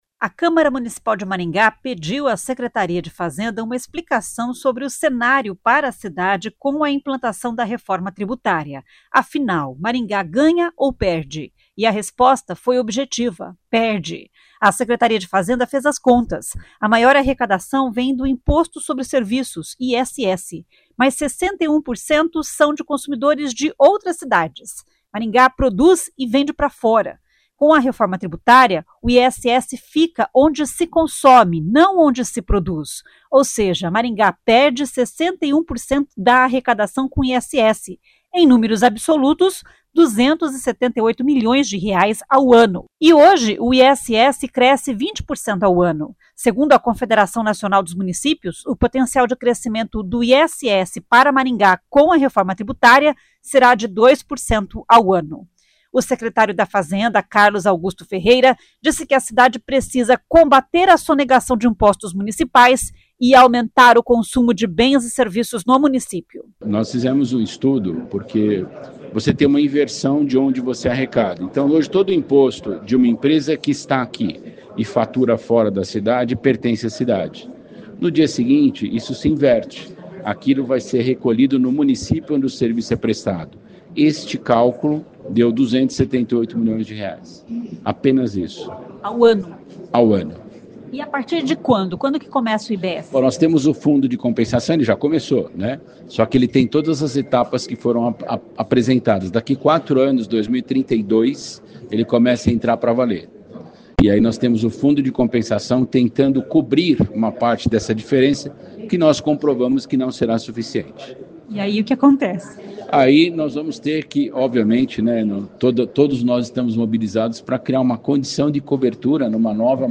O Secretário da Fazenda, Carlos Augusto Ferreira, disse que a cidade precisa combater a sonegação de impostos municipais e aumentar o consumo de bens e serviços no município.
Os dados foram apresentados durante a sessão ordinária da Câmara Municipal nesta quinta-feira (5).